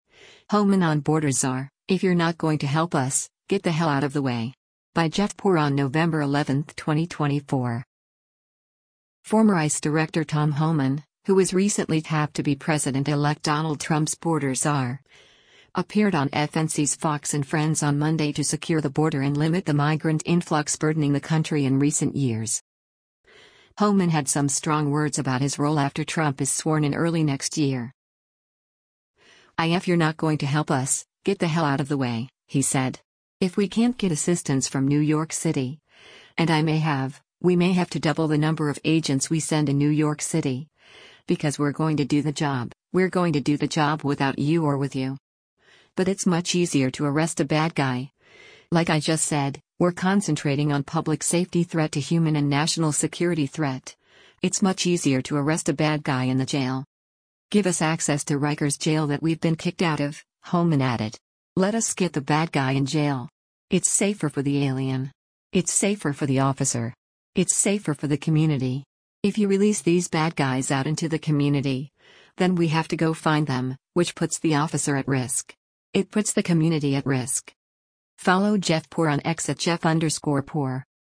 Former ICE Director Tom Homan, who was recently tapped to be President-elect Donald Trump’s border czar, appeared on FNC’s “Fox & Friends” on Monday to